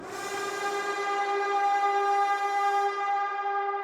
Stab